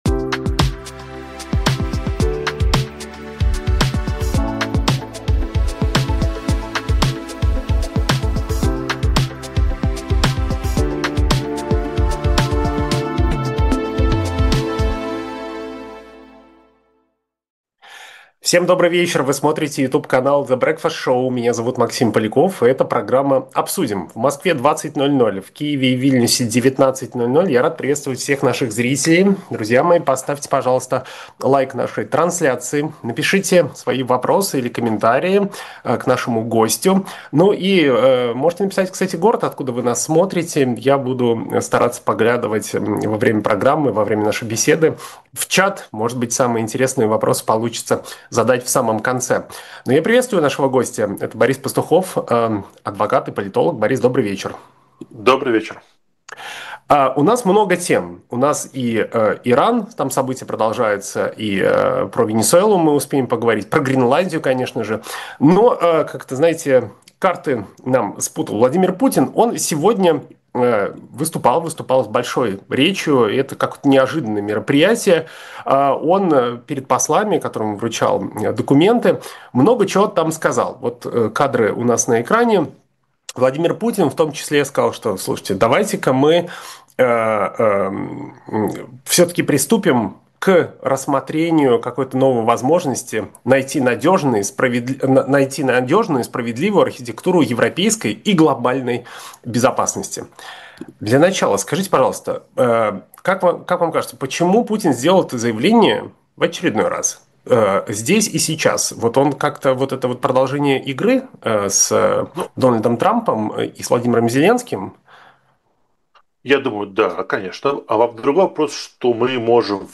политолог